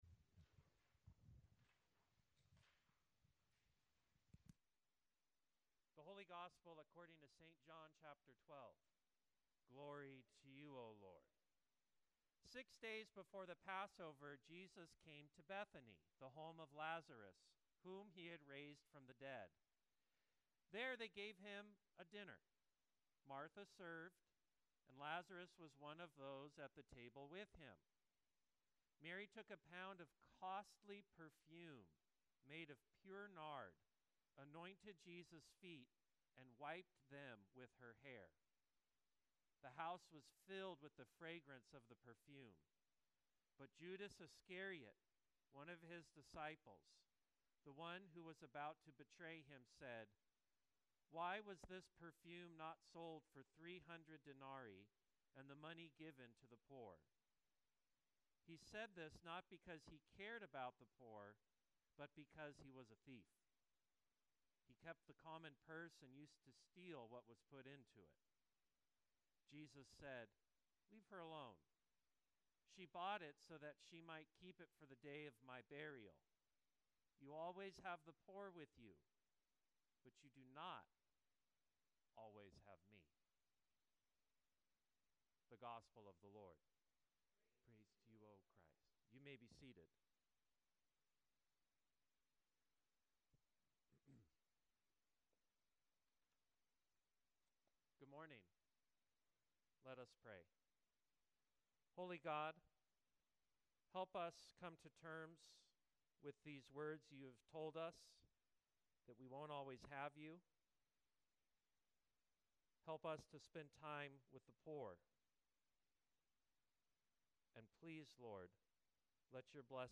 Sermon 04.06.25